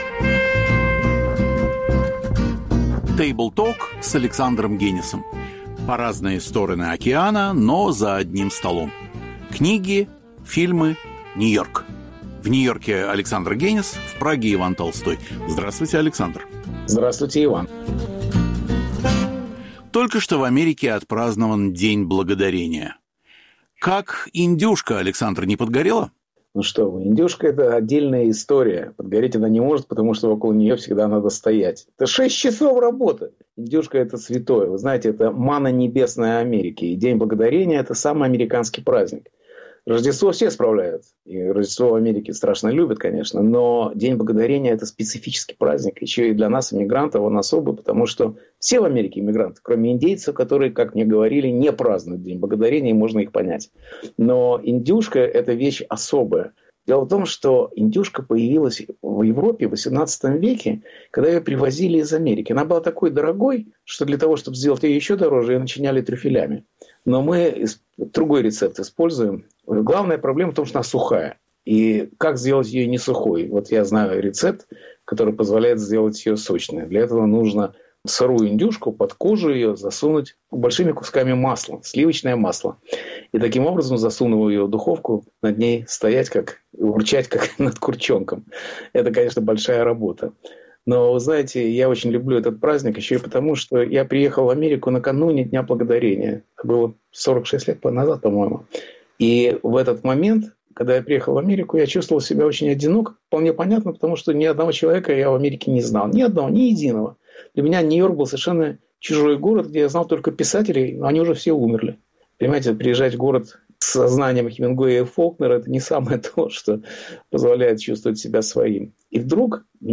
Беседа с А. Генисом о культурных новостях декабря.